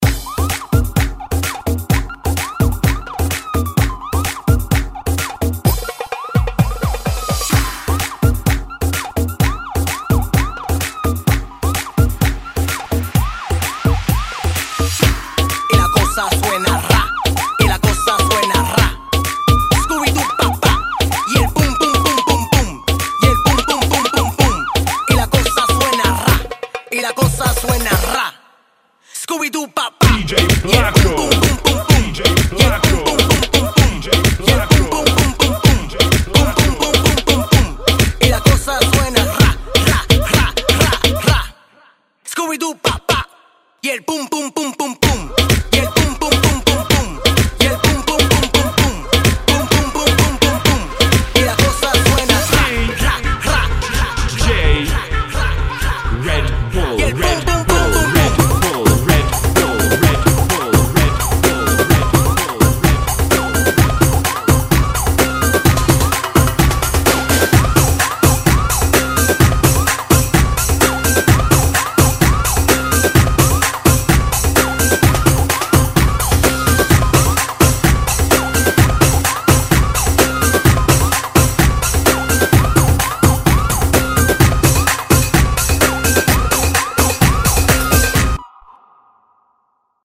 [ 128 bpm ]